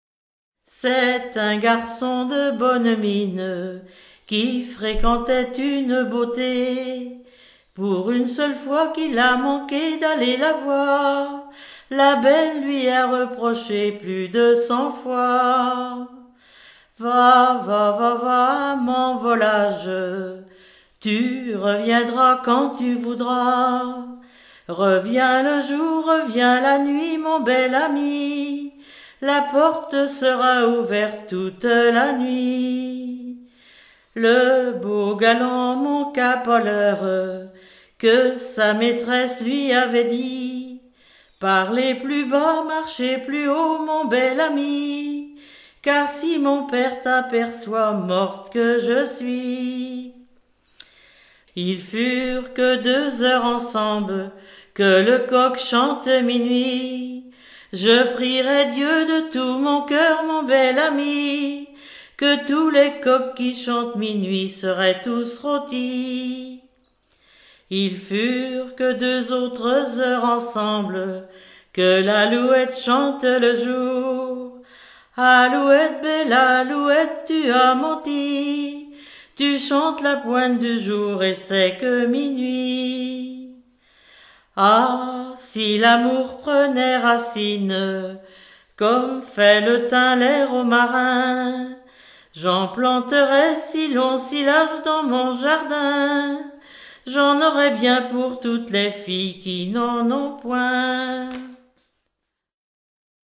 Sigournais ( Plus d'informations sur Wikipedia ) Vendée
Genre strophique
Pièce musicale éditée